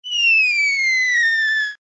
MG_cannon_whizz.ogg